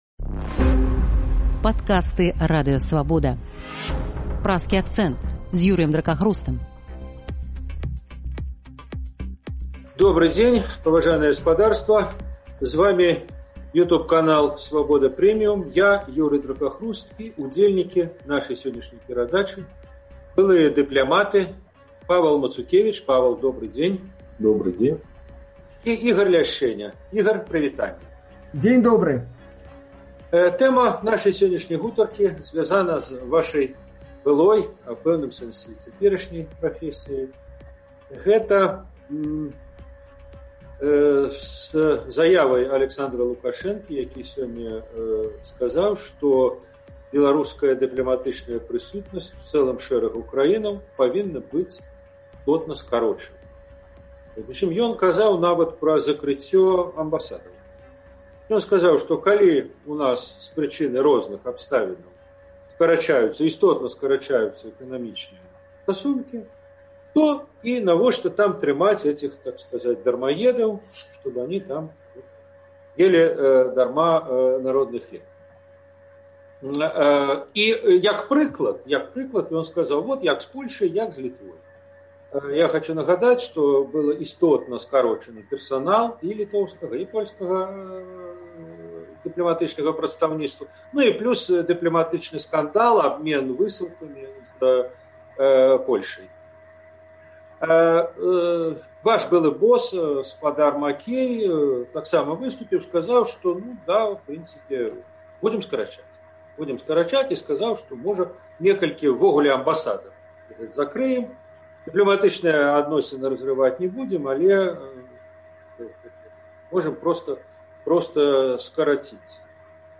Гэтыя тэмы ў «Праскім акцэнце» абмяркоўваюць былыя дыпляматы